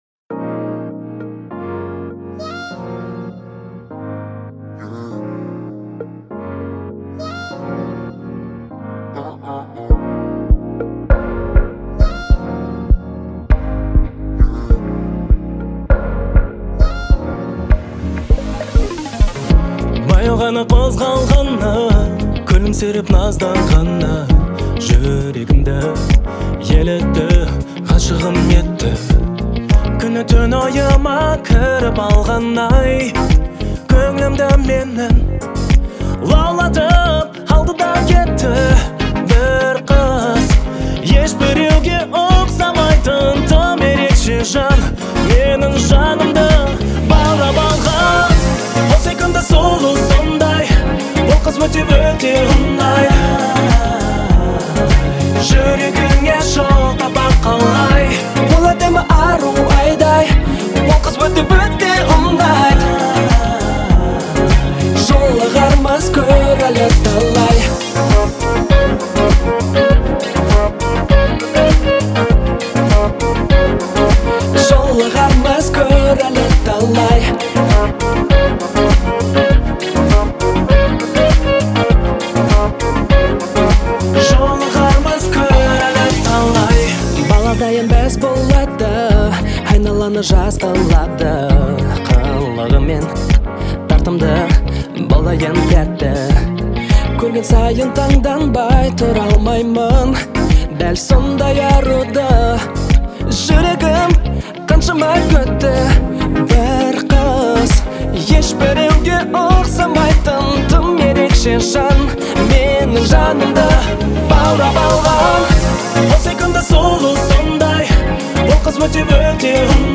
это яркий образец казахского поп-фолка